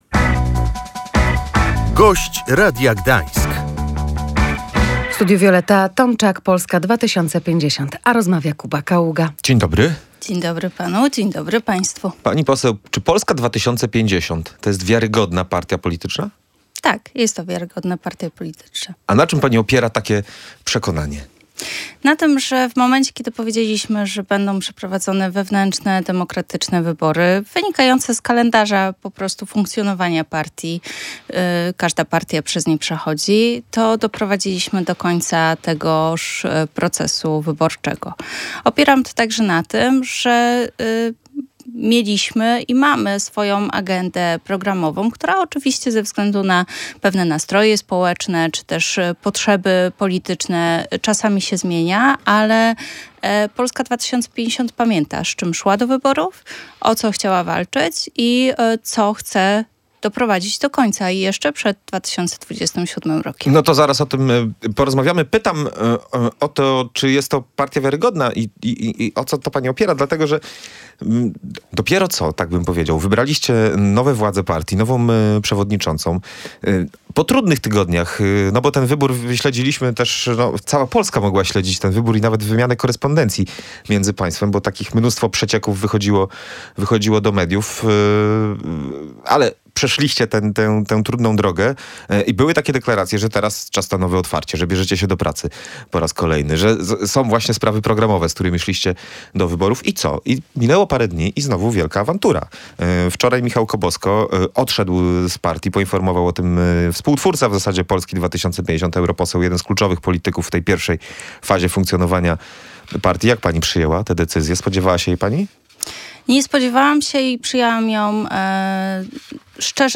Powodami kłopotów w Polsce 2050 są konflikty personalne oraz brak polityki wewnętrznej i komunikacji – mówiła w Radiu Gdańsk Wioleta Tomczak, posłanka partii z Pomorza.